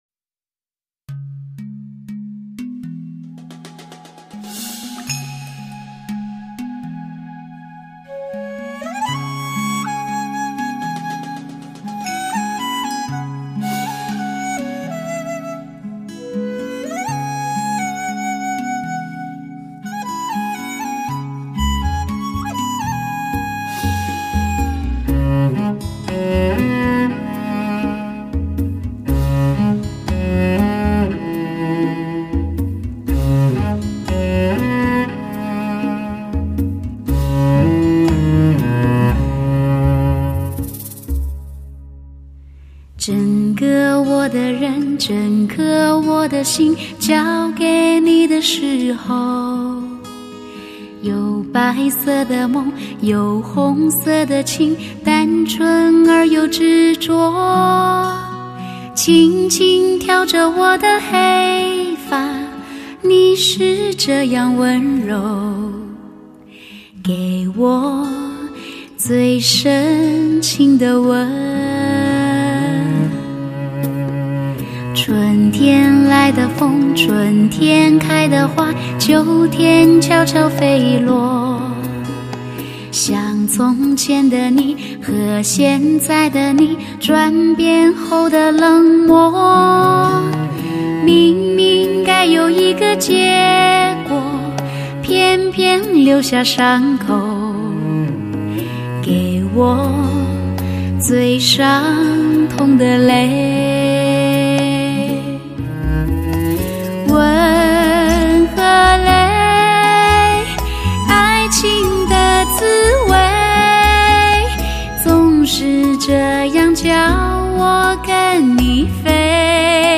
现代发烧碟
2007中国最甜美女声发烧天碟
哪一段流转在被遗忘的时光？这一刻流光飞舞在靡靡女声婉约成诗